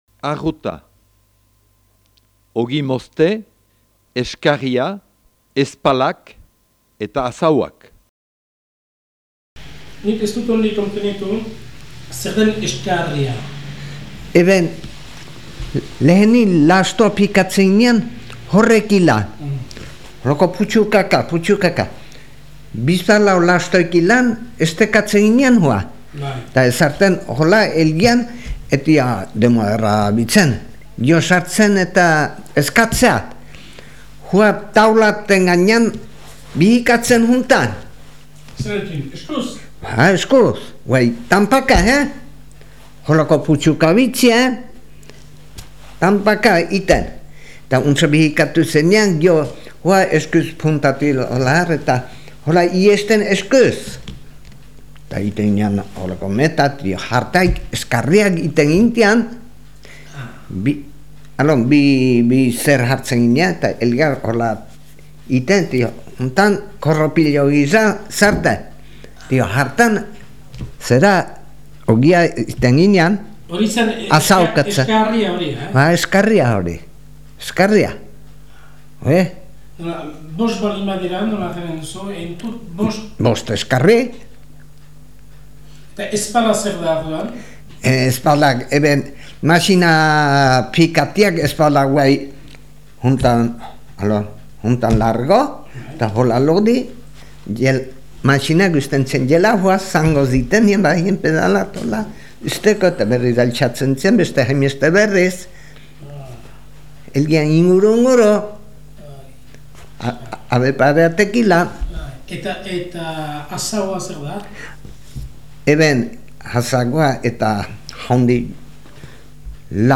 6.8. ARRUTA-SARRIKOTA-AMIKUZE
Inkestagilea, hemen, ogia edo garia mozten zelarik egiten ziren ogi-zangoen multzo zenbaiten izendaketaz ari da. 'Eskarri', 'espal' eta 'azago' hiru gauza direla azaltzen du lekukoak.